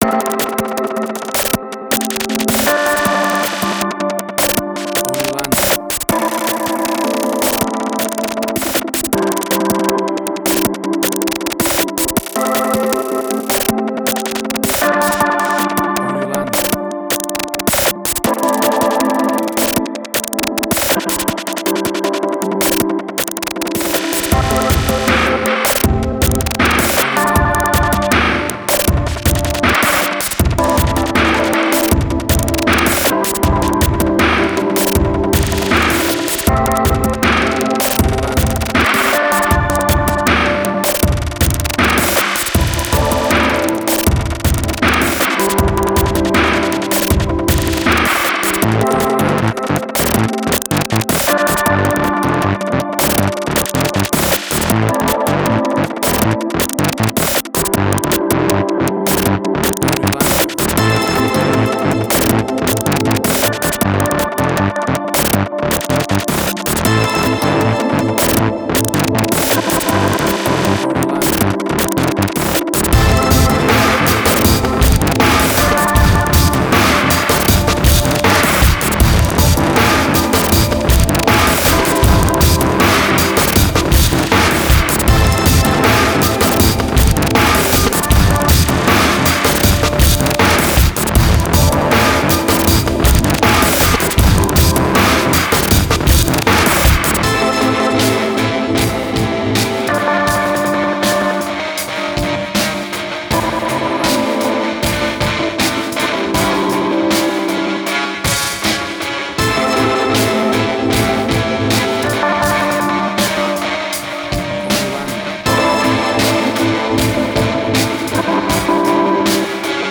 IDM, Glitch.
Tempo (BPM): 80